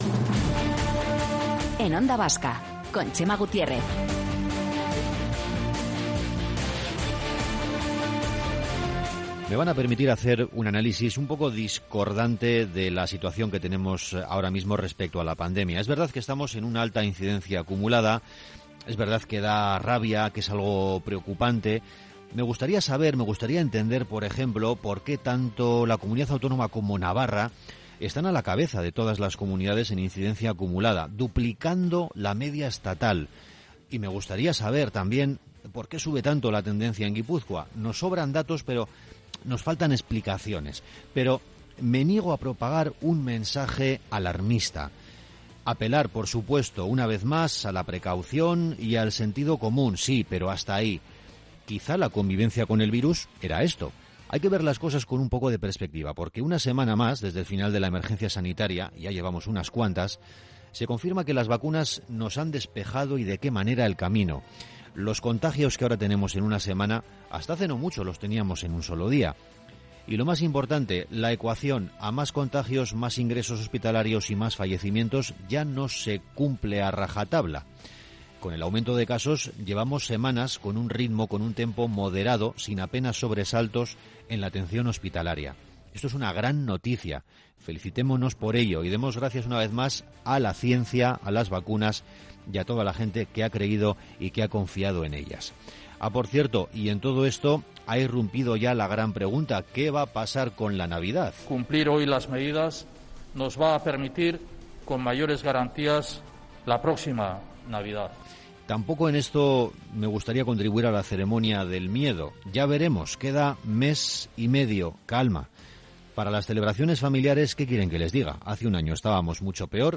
El editorial